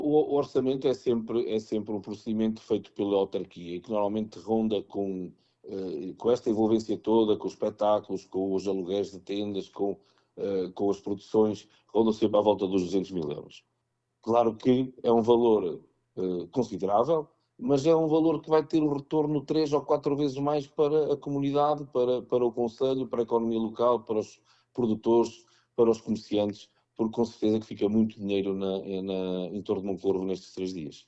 O autarca acrescenta que o orçamento da feira ronda os 200 mil euros e que o investimento tem um retorno expressivo para a comunidade, beneficiando a economia local e os produtores da região: